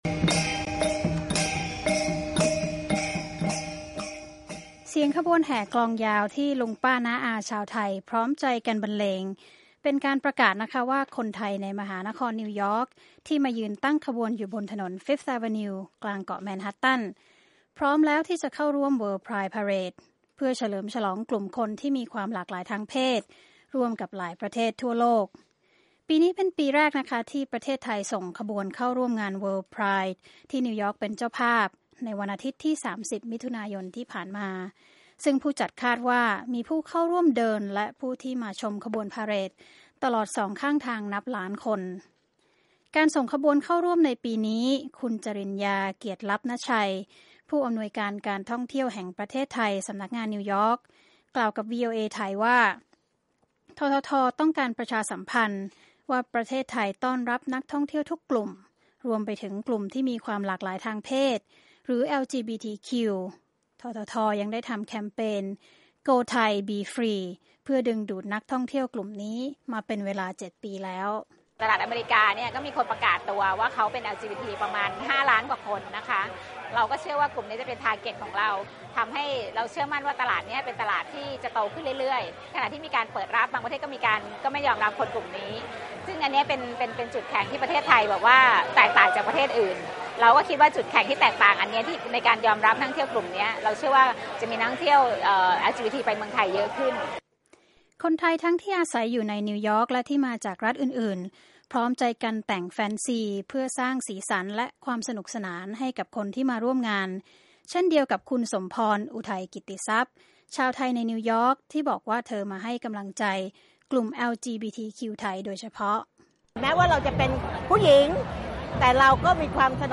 เสียงขบวนแห่กลองยาว ที่ลุงป้าน้าอาชาวไทยพร้อมใจกันบรรเลง เป็นการประกาศว่าคนไทยในมหานครนิวยอร์กที่มายืนตั้งขบวนอยู่บนถนน Fifth Avenue กลางเกาะแมนฮัตตัน พร้อมแล้วที่จะเข้าร่วม World Pride Parade เพื่อเฉลิมฉลองกลุ่มคนที่มีความหลากหลายทางเพศร่วมกับหลายประเทศทั่วโลก